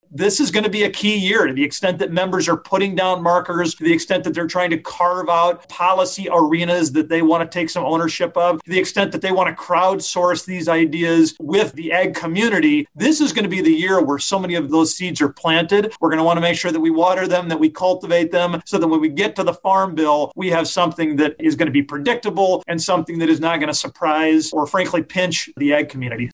Agri-Pulse recently held a webinar to talk about the top issues facing rural America this year and what might be ahead in the 117th Congress for potential answers to those challenges.
One of the speakers was South Dakota Republican Congressman Dusty Johnson.